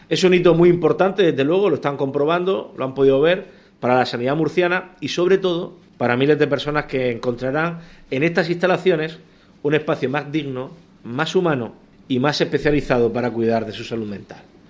Sonido/ Declaración 2 del presidente de la Comunidad, Fernando López Miras, sobre los nuevos recursos de Salud Mental puestos en marcha por la Comunidad.
López Miras visita la nueva planta de enfermos agudos de la Unidad de Psiquiatría y Salud Mental del Hospital Morales Meseguer de Murcia.